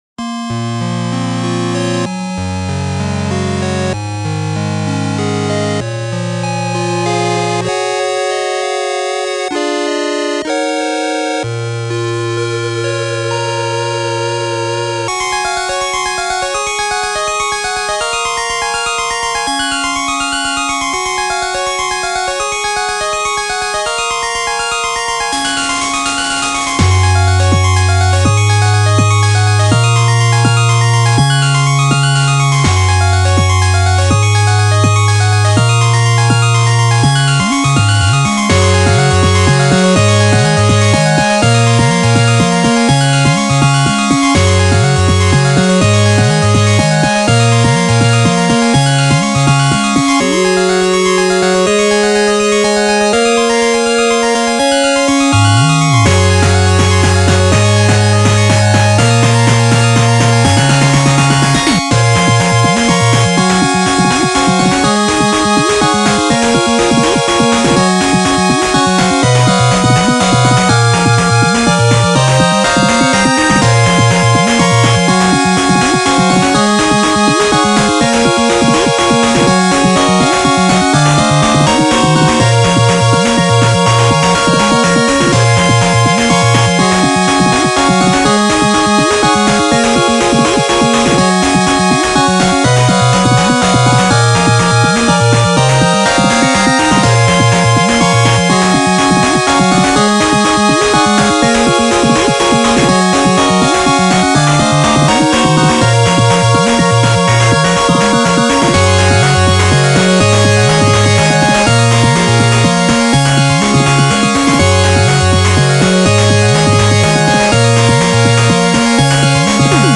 ジャンルファミコン風、8-Bit
使用例ボス戦闘、躍動感のある動画
BPM６４→８２
使用楽器8-Bit音源
原曲がEDMなので、違和感なくアレンジできました。
ピコピコ感が強く出ているので、可愛く仕上がっております。